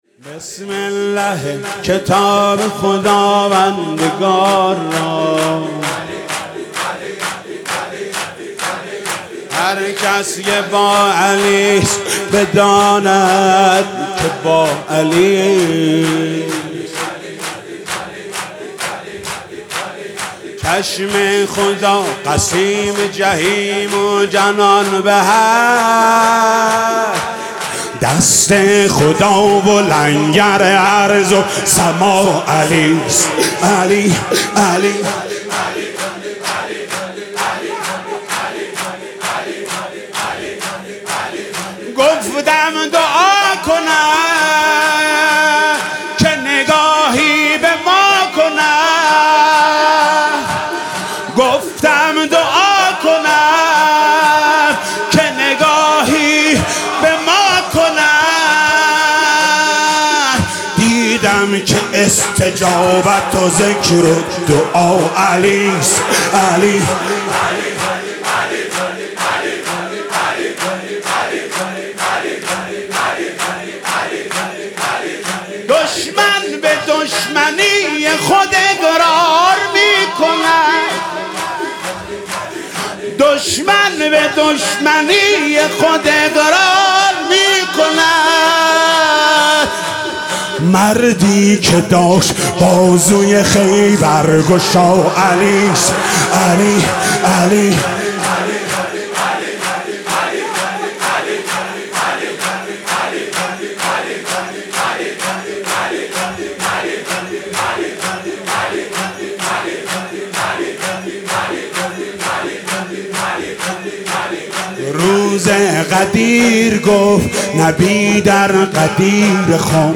فاطمیه 95 - واحد - بسم الله ای کتاب خداوندگار را